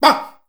BAM.wav